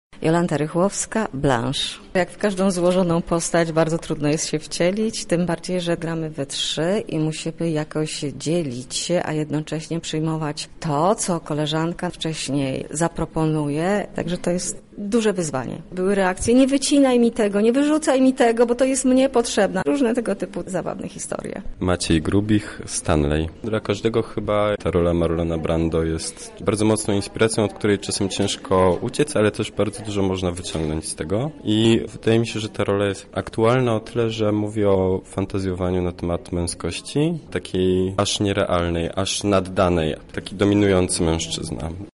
Aktorzy Teatru Osterwy podzielili się z naszą reporterką przemyśleniami na temat swoich ról.